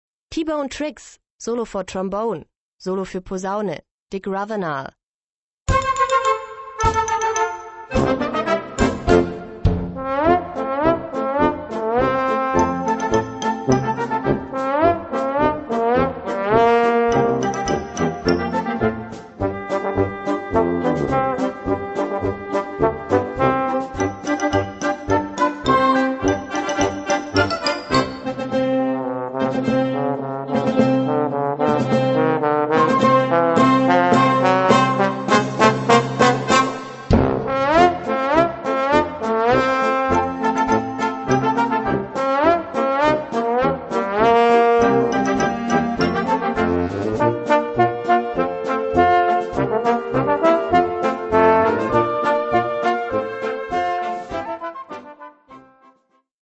Gattung: Solo für Posaune und Jugendblasorchester
Besetzung: Blasorchester